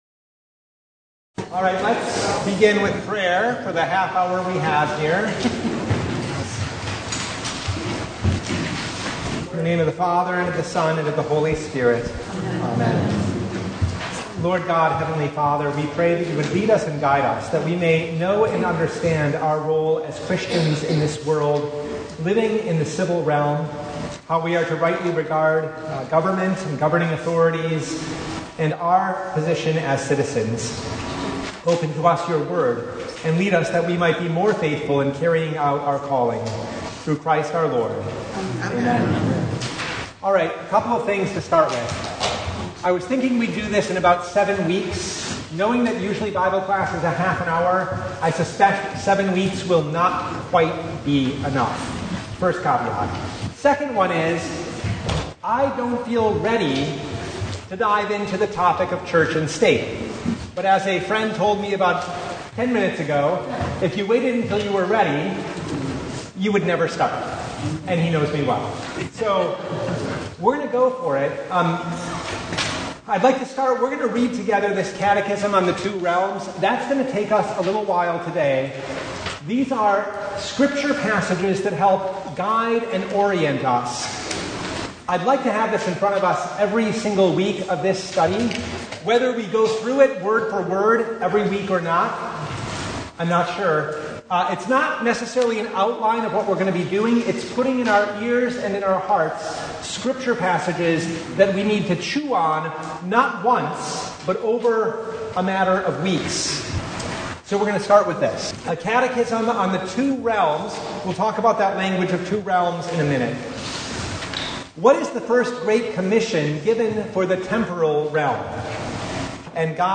Service Type: Bible Hour
Bible Study « Ash Wednesday Noon Service